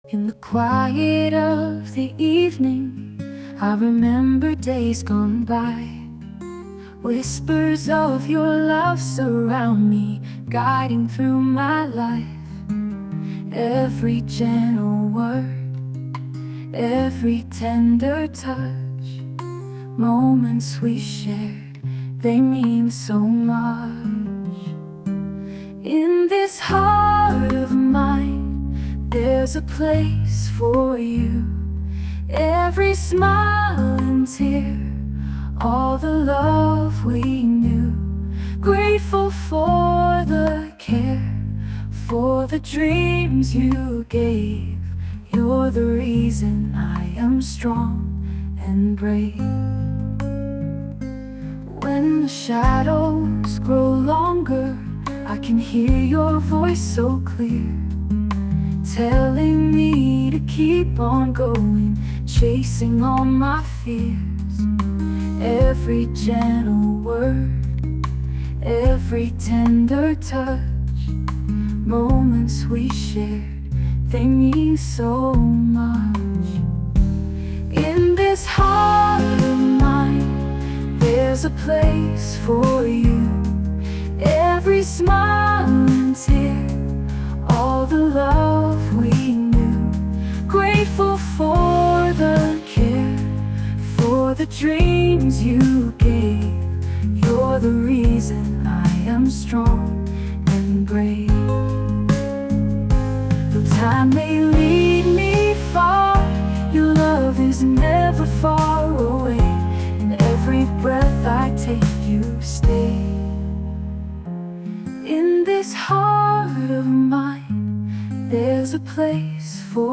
洋楽女性ボーカル著作権フリーBGM ボーカル
女性ボーカル曲（英語）です。